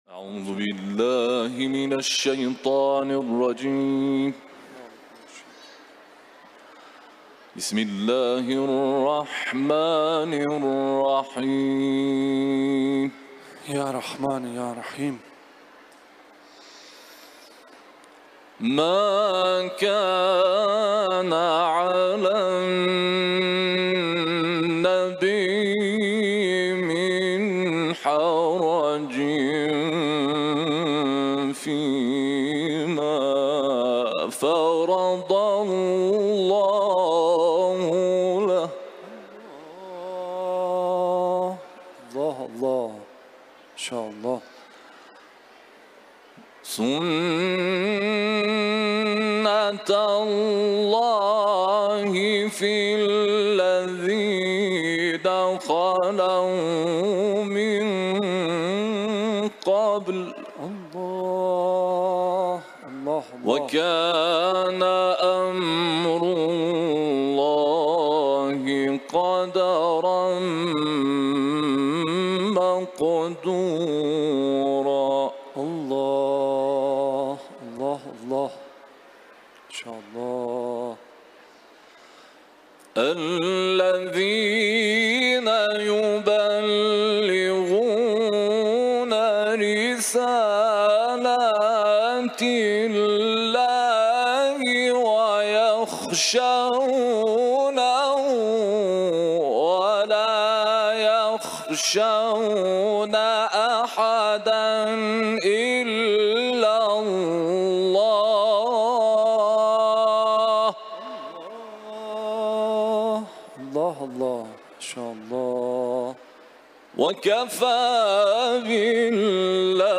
Kur’an-ı Kerim tilaveti